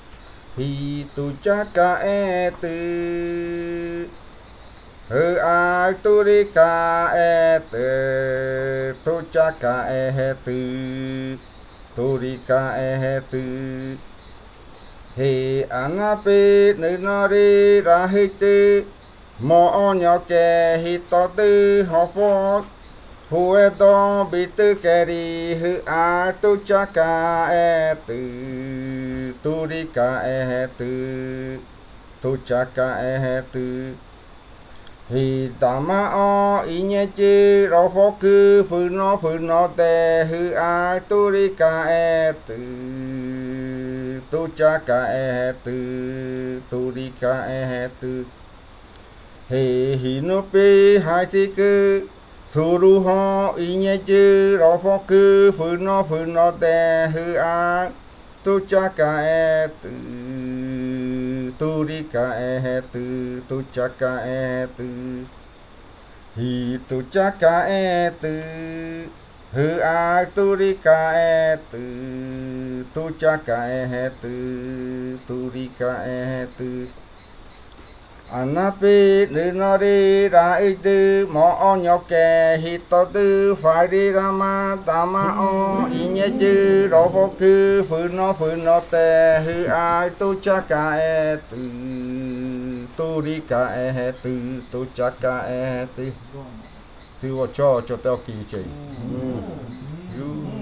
Leticia, Amazonas, (Colombia)
Grupo de danza Kaɨ Komuiya Uai
Canto de fakariya de la variante jaiokɨ (canntos de culebra).
Fakariya chant of the Jaiokɨ variant (Snake chants).